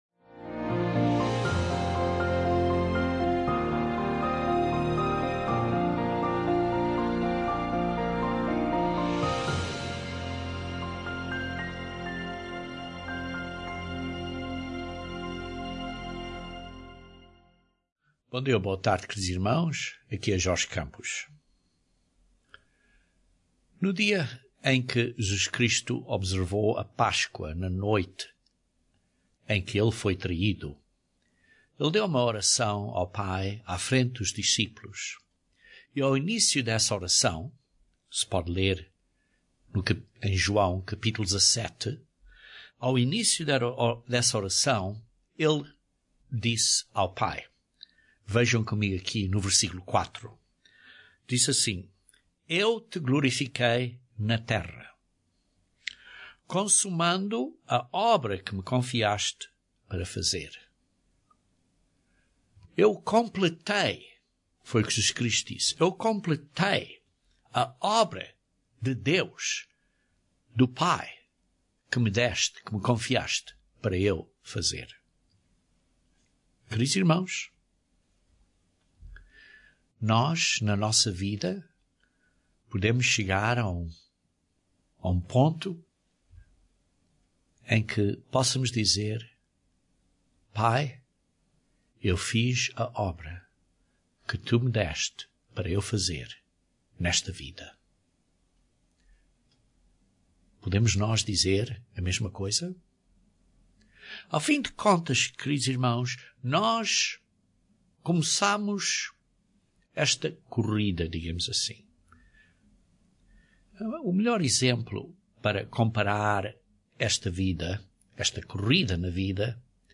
Vamos manter-nos fieis até ao fim? Este sermão analiza este tema pelo ponto de vista da soberba e o pecado.